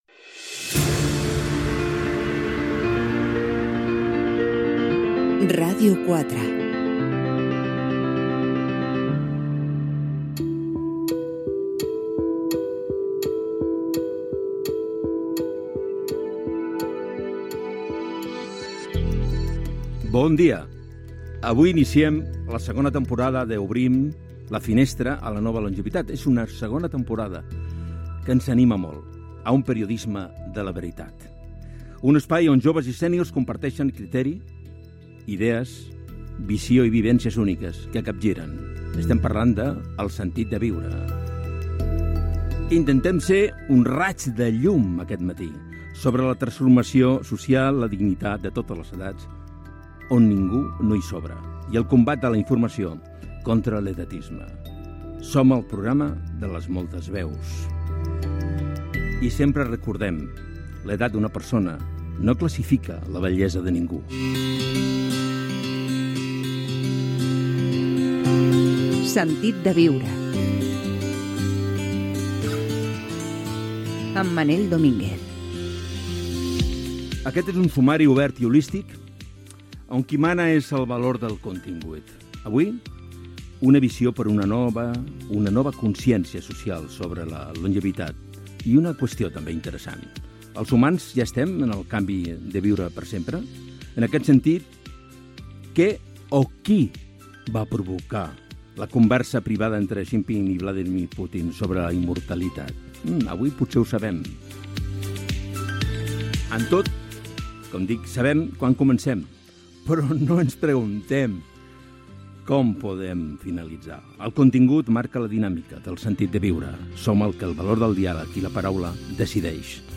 Indicatiu del programa, presentació del programa sobre l'edatisme i la longevitat maltractada.